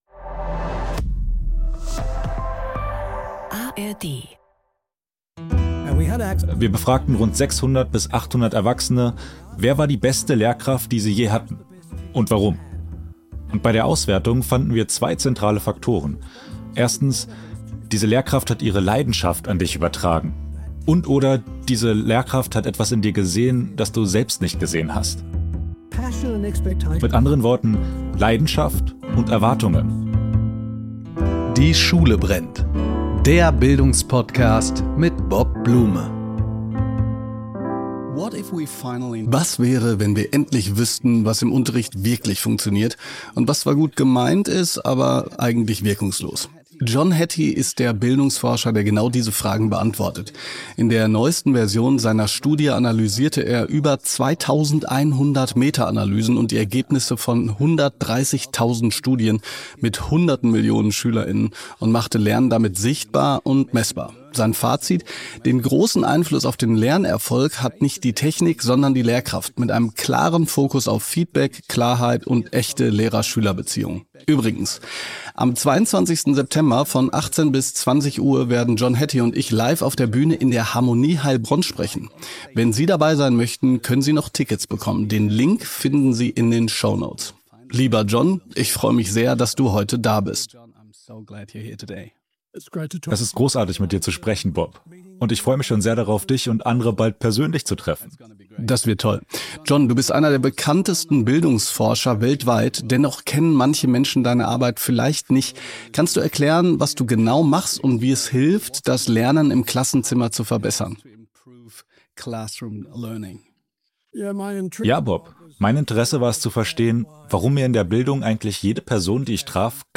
Darüber, über Kritik an seiner Forschung und warum viele SchülerInnen Unterricht langweilig finden, erzählt John Hattie im Talk.